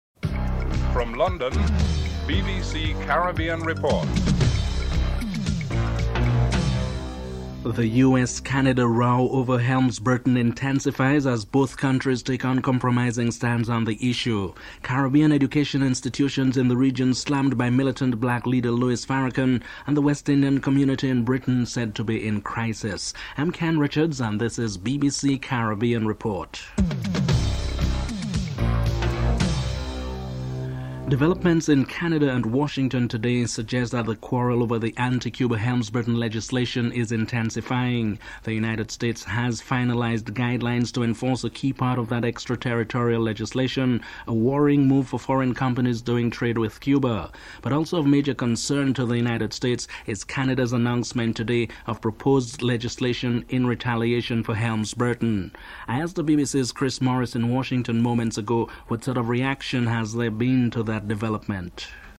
Headlines (00:00-00:30)